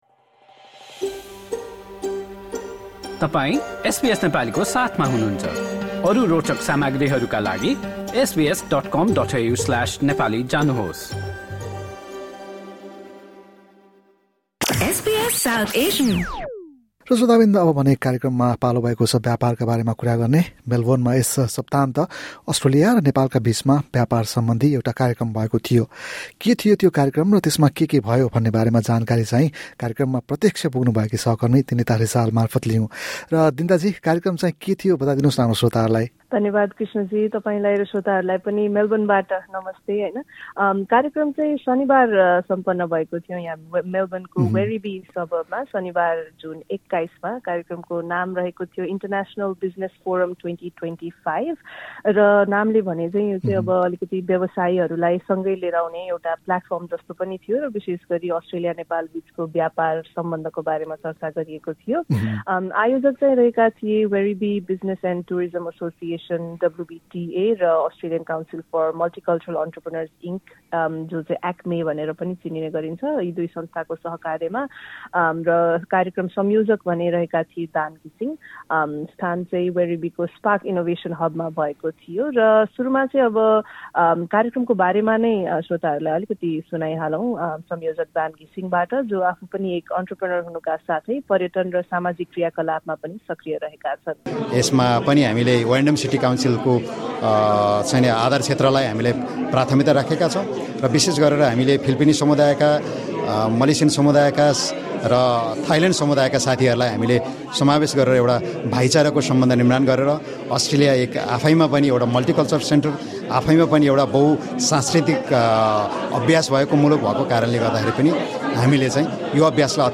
On Saturday, 21 June, the ‘International Business Forum 2025’ was held in Werribee, Melbourne. The event brought together businesspeople from diverse backgrounds.